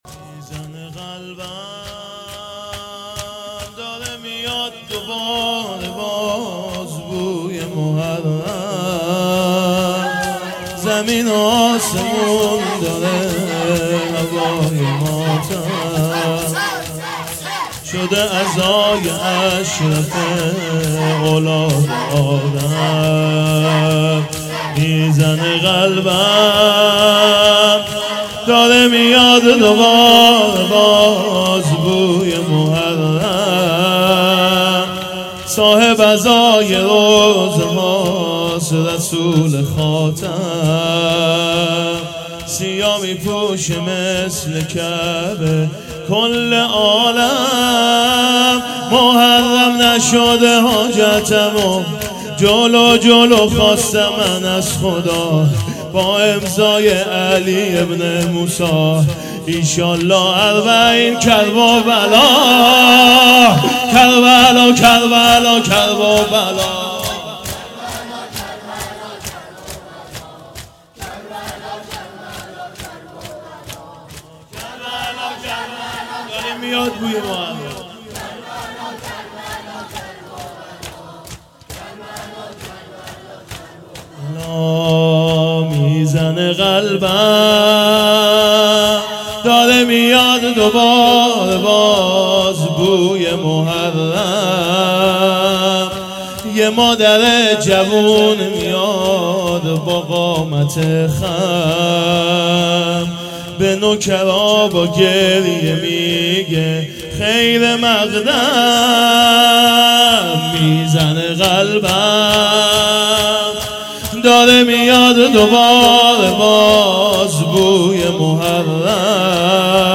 میزنه قلبم داره میاد دوباره باز بوی محرم _ شور
شهادت امام باقر علیه السلام